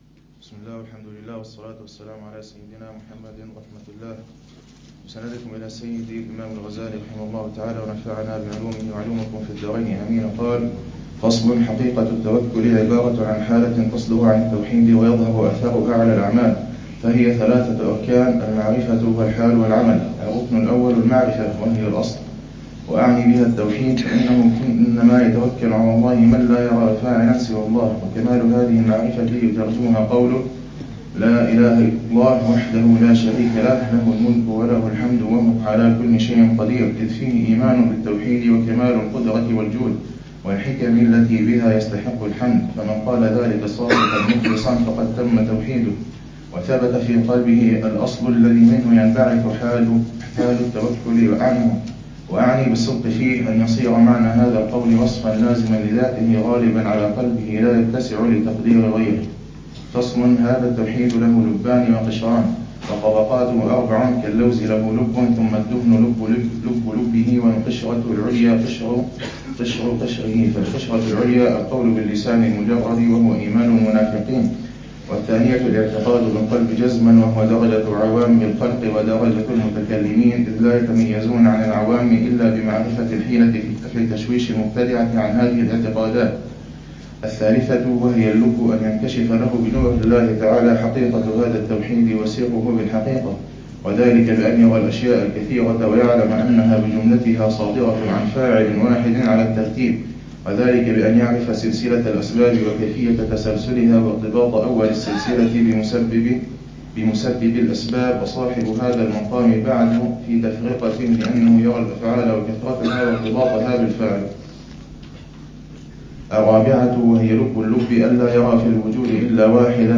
الدرس الثامن والثلاثون للعلامة الحبيب عمر بن محمد بن حفيظ في شرح كتاب: الأربعين في أصول الدين، للإمام الغزالي .